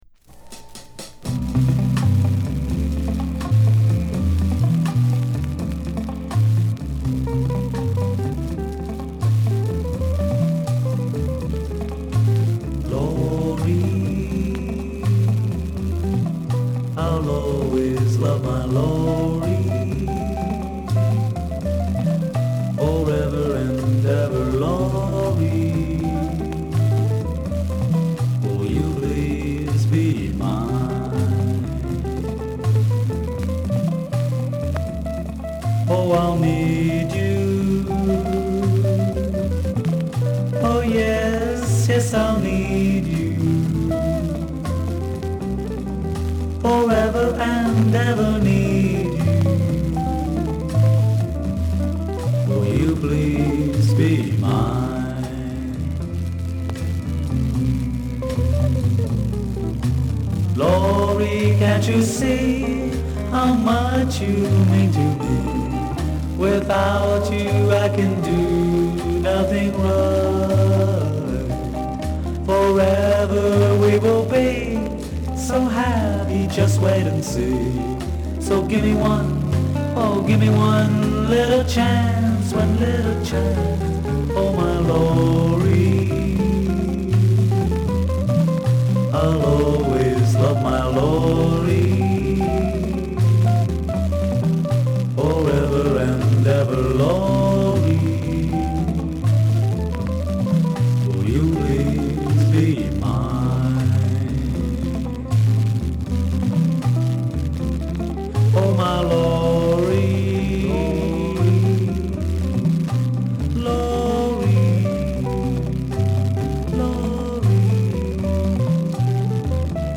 B面はムーディなバラード・ナンバーを披露。鬱な側面もあります。